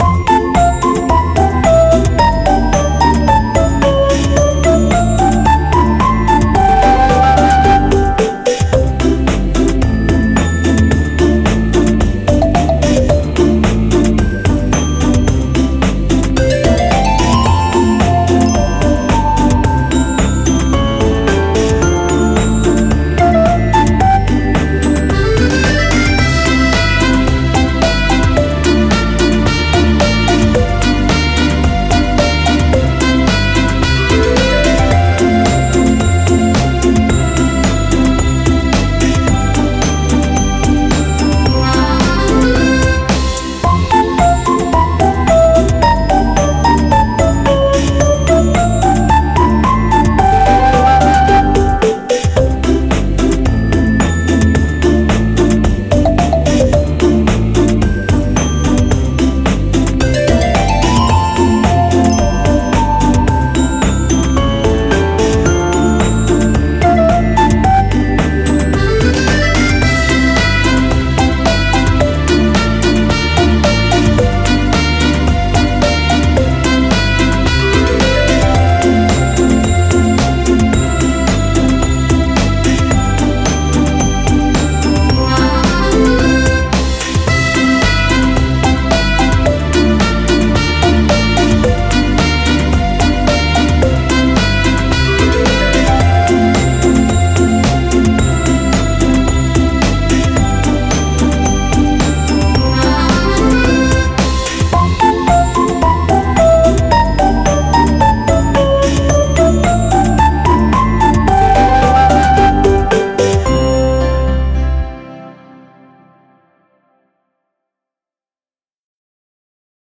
• Категория: Детские песни
Скачать минус детской песни